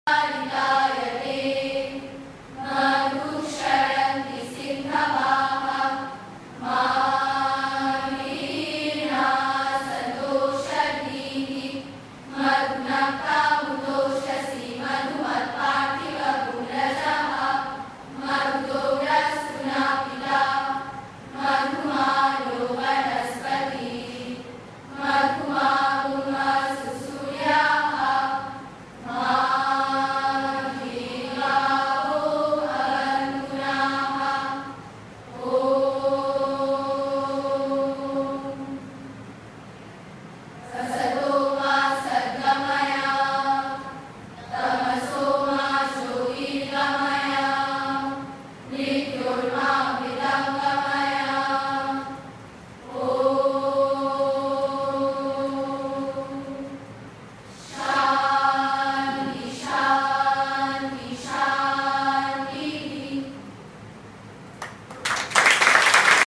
There was no harmonium or any musical instrument to support them, but they sang in melodious voices “Madhuvata Rutayate,”  “Sahana Vavatu…,” a hymn on Sri Sarada Devi, and a Bengali song on Sister Nivedita.
The following are small clips which give some idea of their singing:
prayers_madhuvata_asatoma.mp3